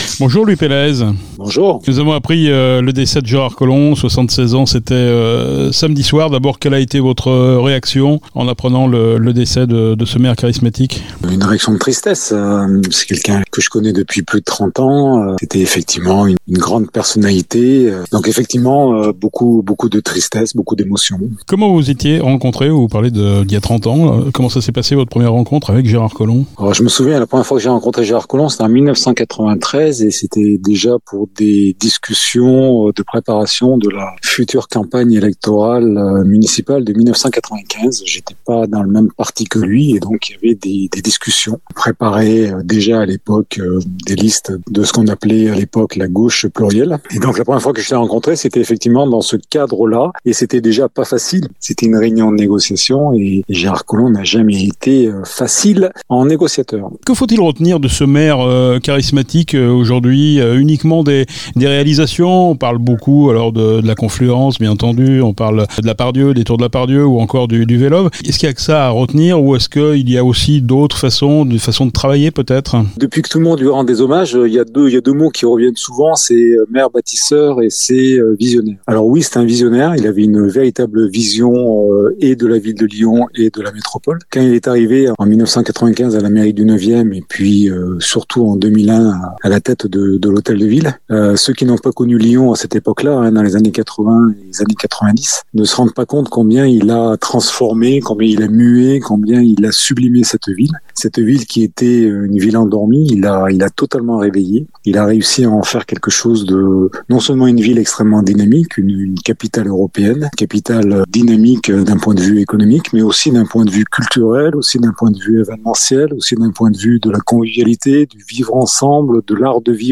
Louis Pelaez est le président du groupe « Inventer la Métropole de Demain« .